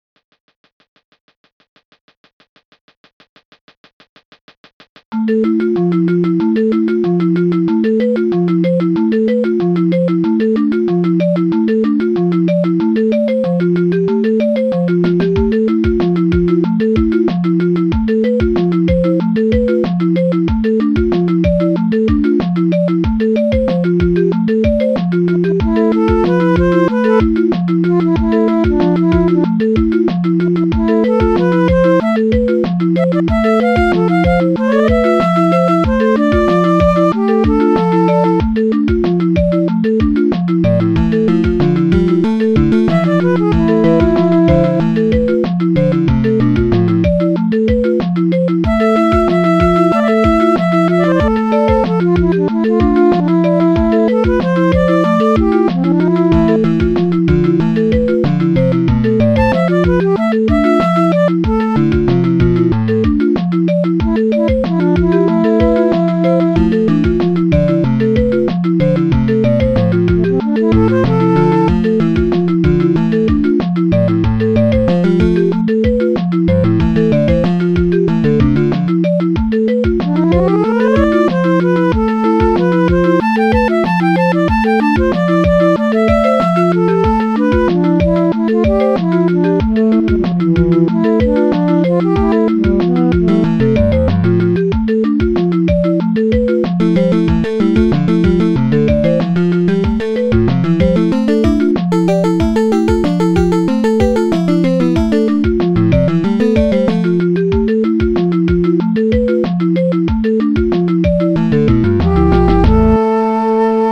2 channels
Tracker DSIK DSM-format
Bass Drum 1 Flute 1 Acoustic Guitar Closed HiHat 1 Snare 1 Vibes 2